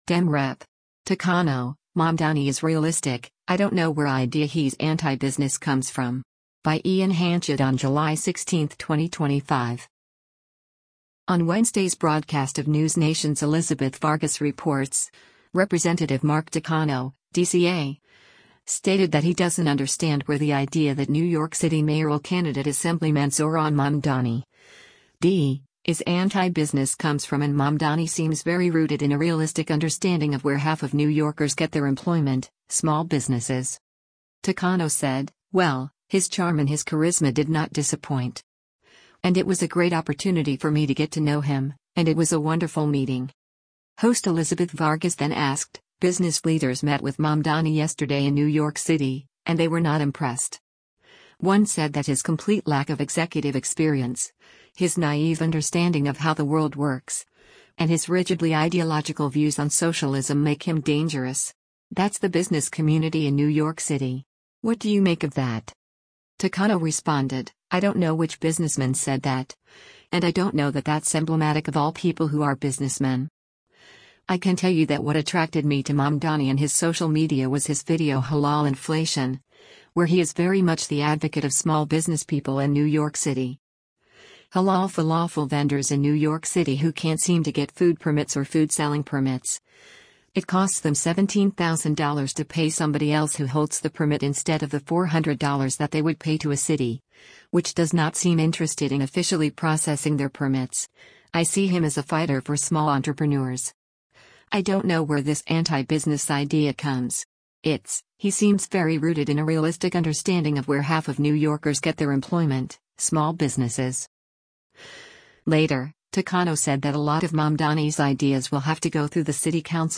On Wednesday’s broadcast of NewsNation’s “Elizabeth Vargas Reports,” Rep. Mark Takano (D-CA) stated that he doesn’t understand where the idea that New York City mayoral candidate Assemblyman Zohran Mamdani (D) is anti-business comes from and Mamdani “seems very rooted in a realistic understanding of where half of New Yorkers get their employment, small businesses.”